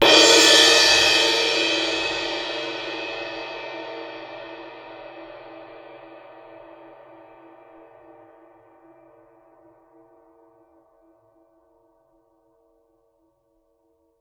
susCymb1-hit_fff_rr2.wav